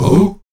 Index of /90_sSampleCDs/Roland LCDP11 Africa VOL-1/VOX_Afro Chants/VOX_Ah Oos
VOX AHOO G09.wav